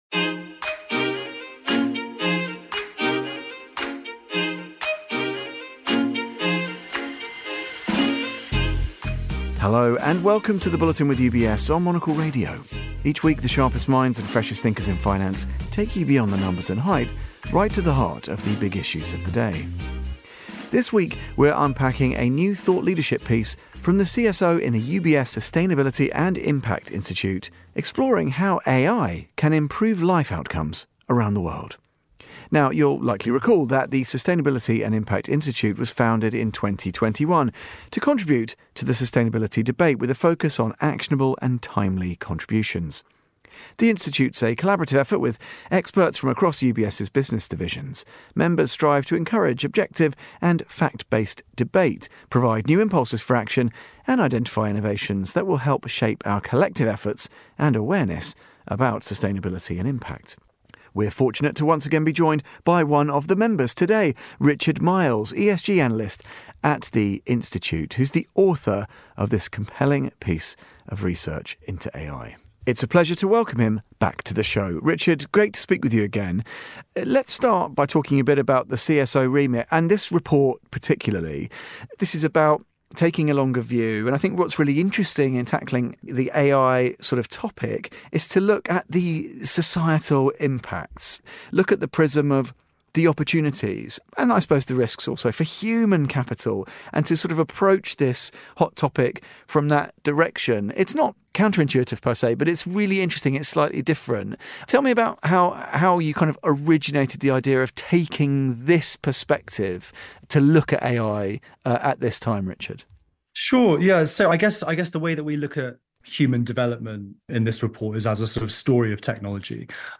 A discussion on the social risk and opportunities in the AI-transition.